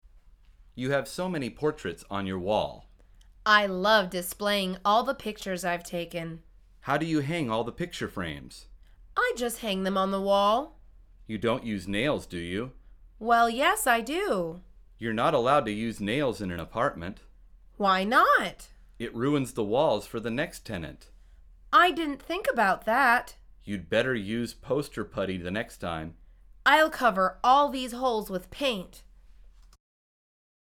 مجموعه مکالمات ساده و آسان انگلیسی – درس شماره پنجم از فصل مسکن: میخ توی دیوار